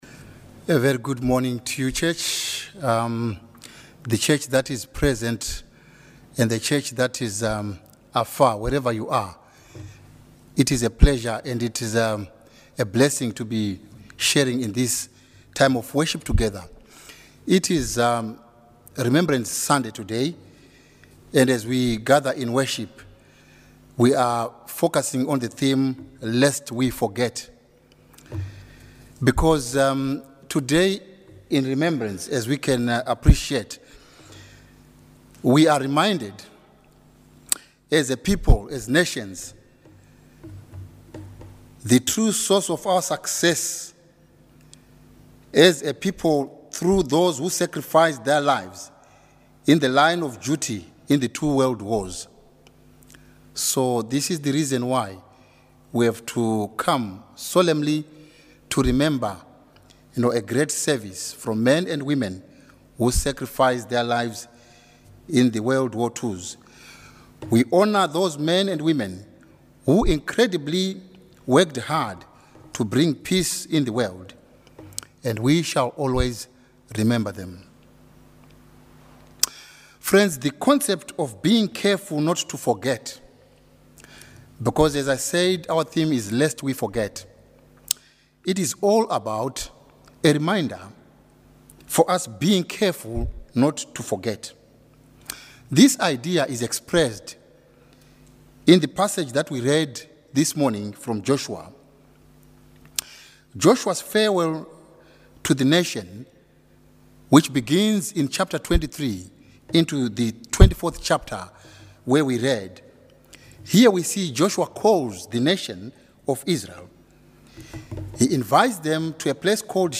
Our service for Remembrance Sunday.
latestsermon-1.mp3